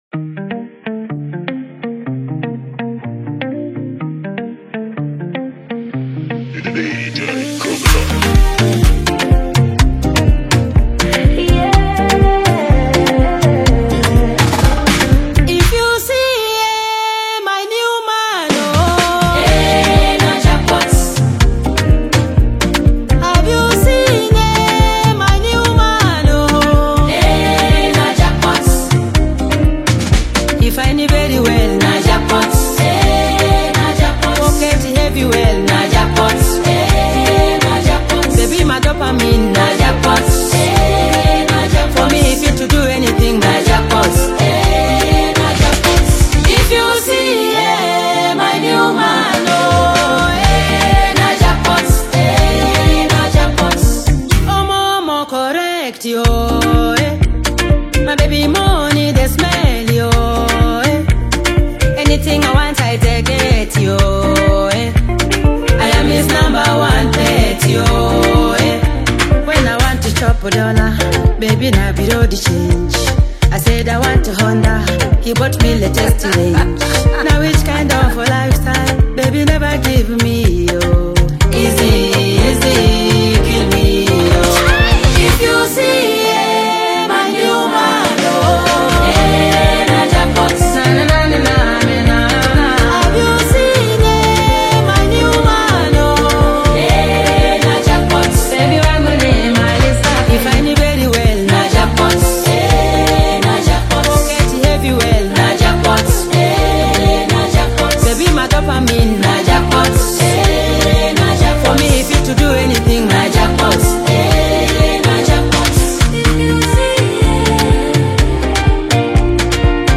is a lively Kenyan Afro-Pop/Afrobeats single